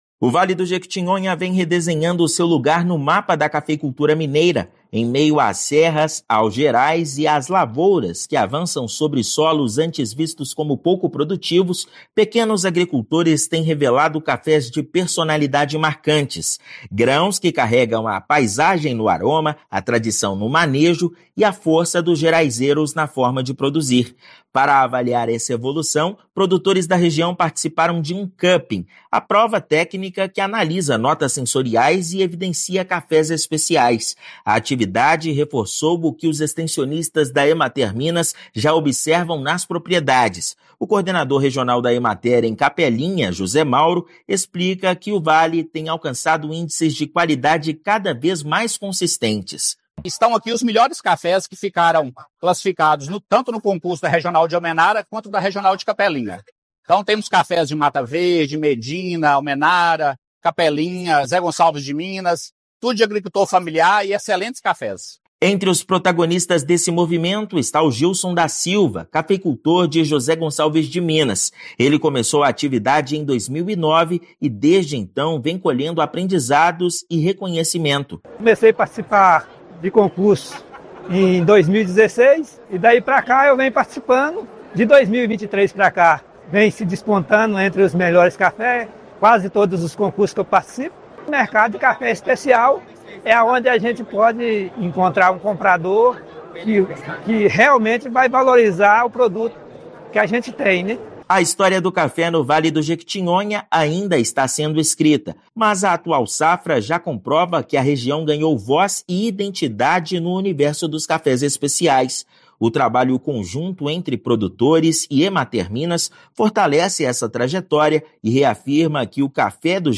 Agricultores familiares da região investem na atividade e ganham mercado. Ouça matéria de rádio.